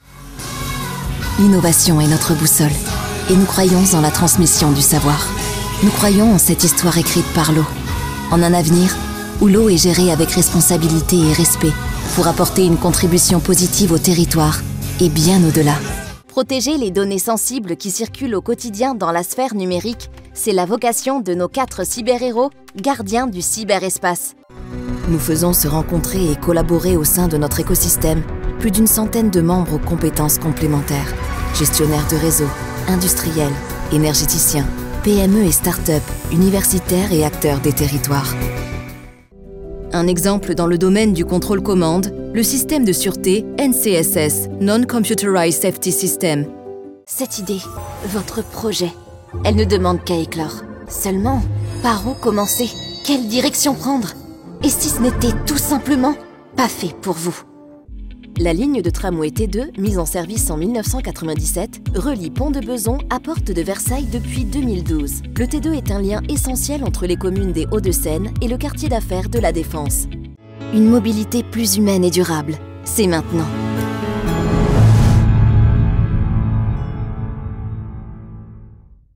Natural, Travieso, Amable, Joven, Suave
Corporativo